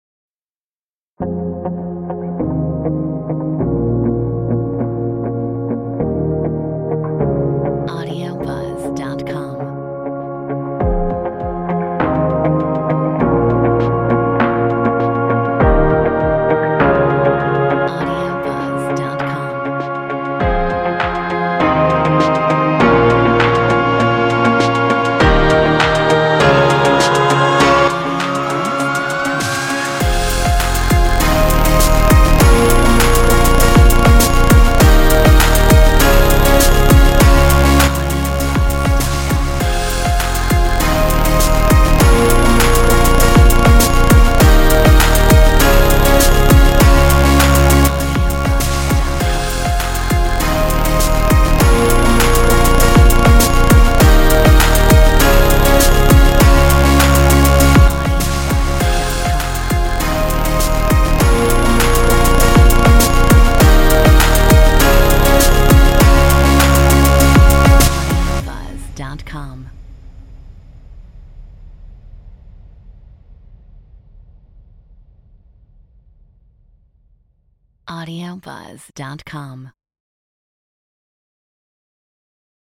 Metronome 100